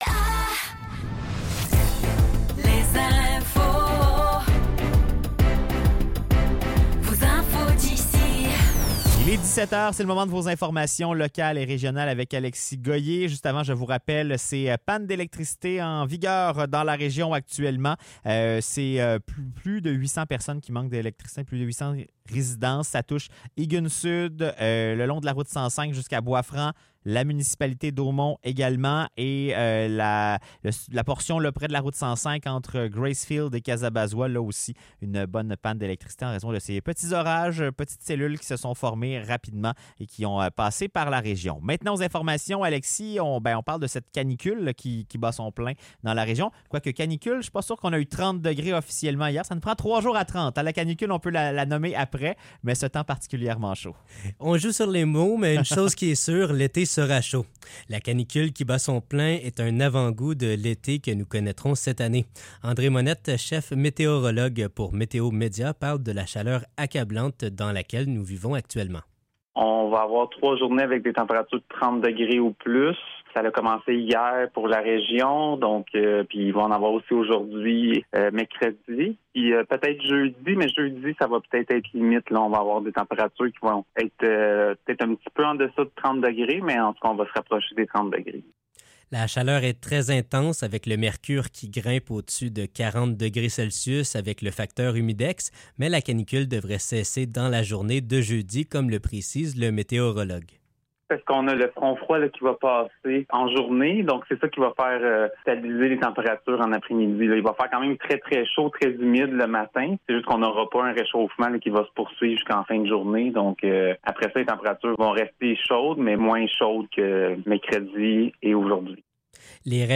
Nouvelles locales - 18 juin 2024 - 17 h